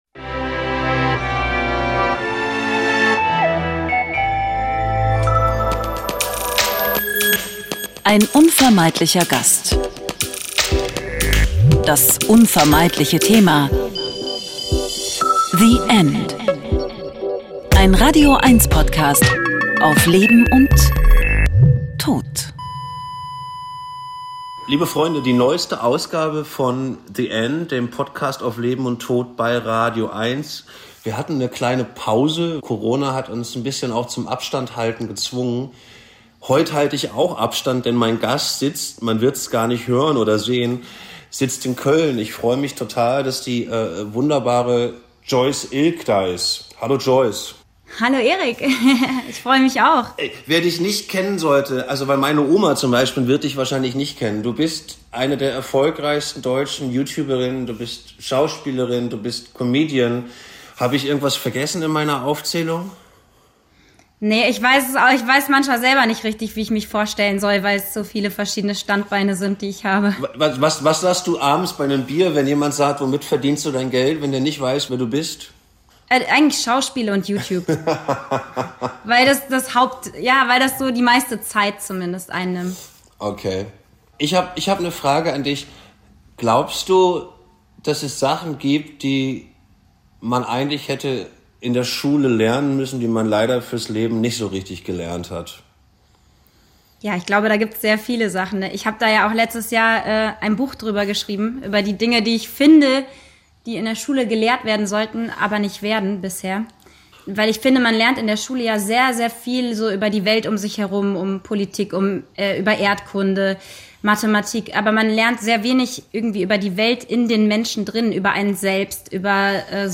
Ein Gespräch über Verlustängste, wie man lernen kann, ihnen zu begegnen, und das Loslassen. Was macht man mit digitalen Hinterlassenschaften im Netz und das Wichtigste, was bedeutet das Alles für unser Leben im Jetzt?